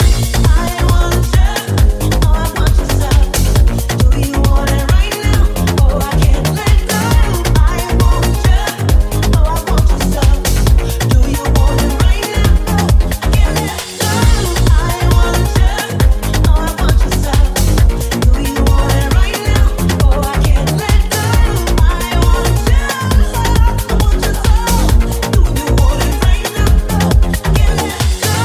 Genere: afrobeat,afrohouse,deep,remix,hit